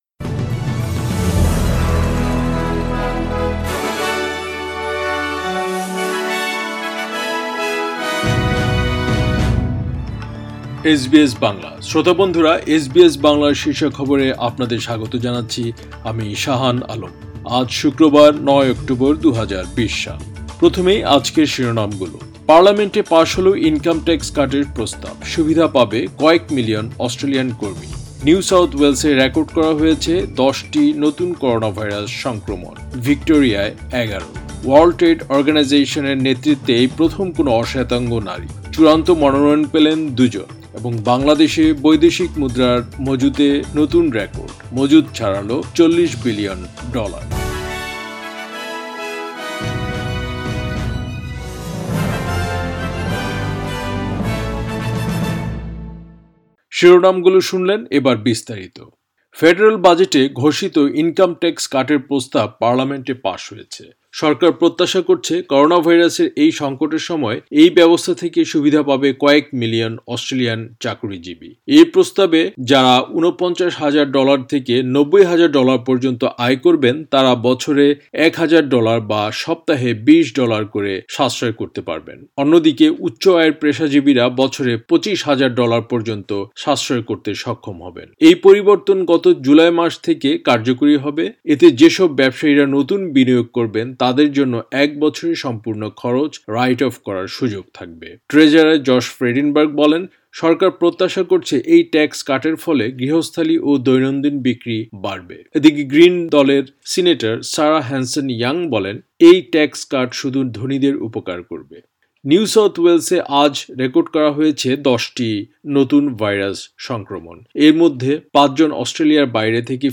এসবিএস বাংলা শীর্ষ খবর, ৯ অক্টোবর, ২০২০